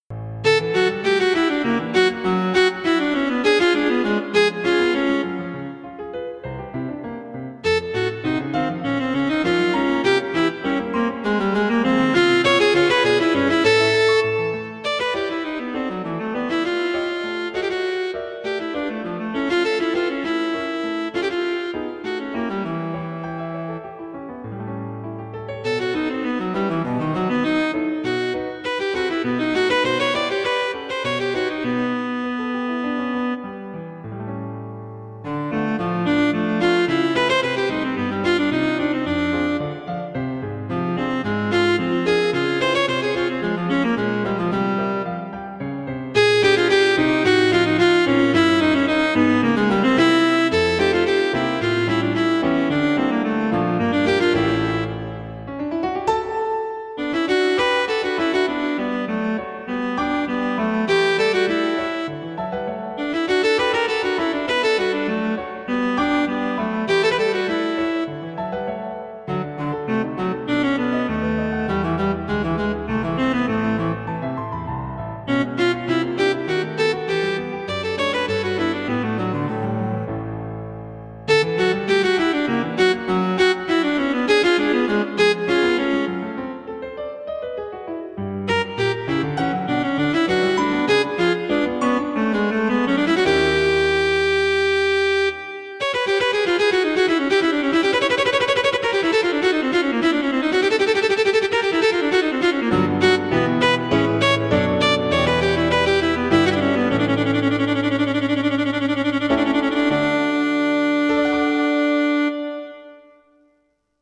Cello and Piano
The audio clip is the third movement - Allegretto.